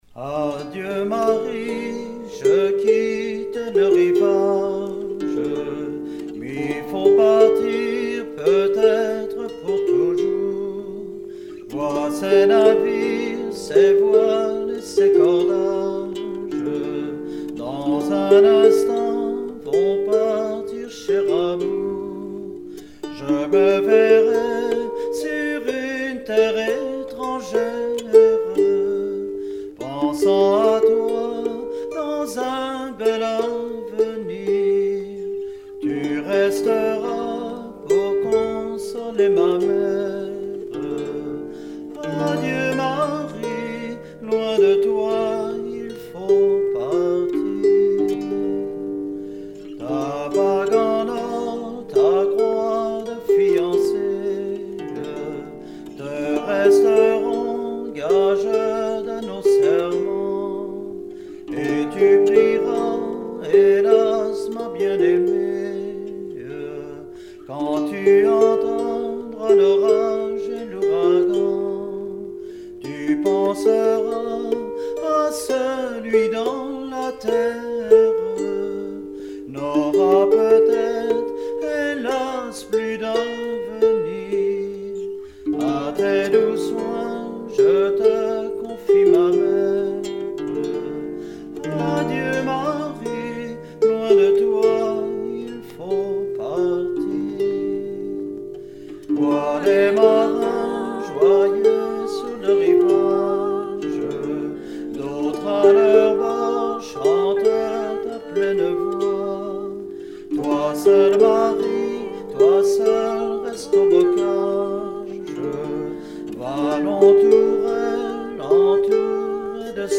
circonstance : maritimes
Genre strophique
Pièce musicale éditée